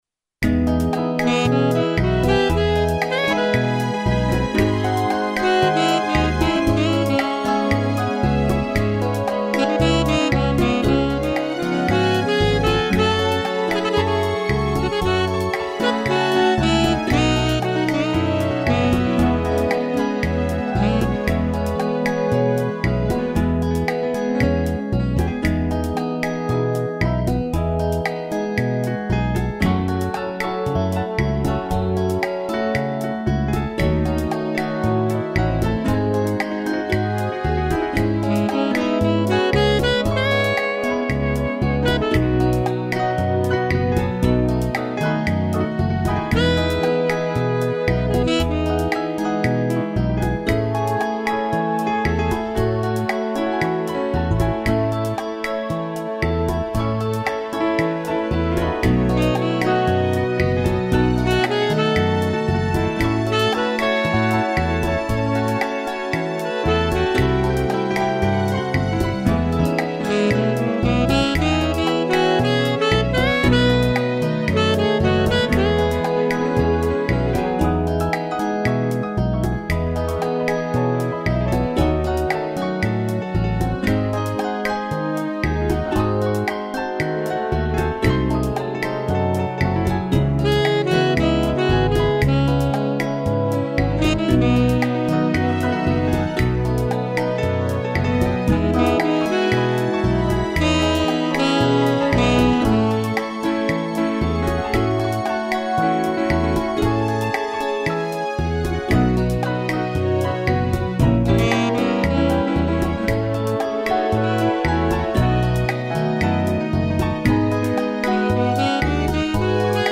piano, sax e strings
(instrumental)